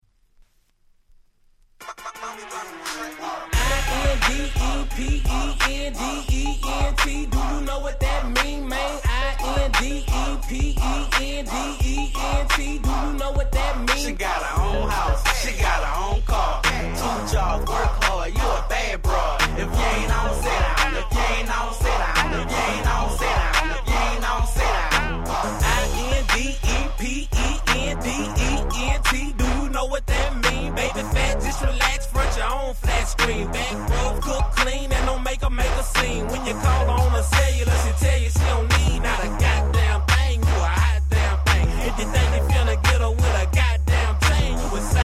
大Hit South !!